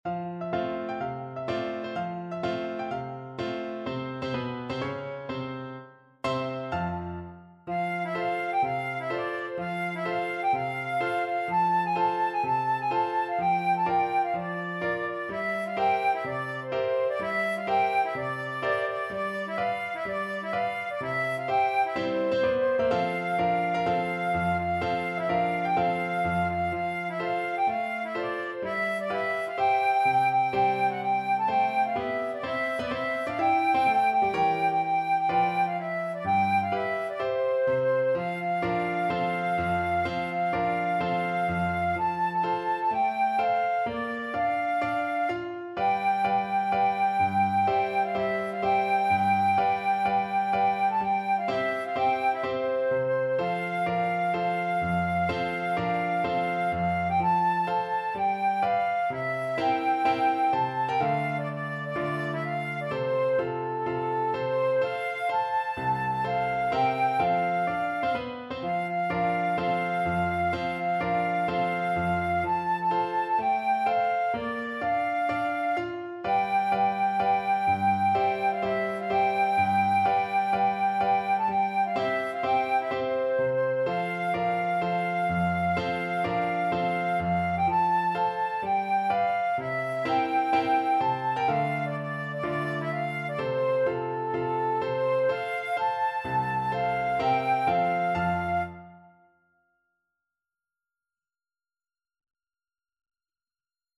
Flute version
Moderato =126
4/4 (View more 4/4 Music)